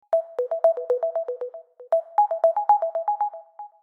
call-sound.mp3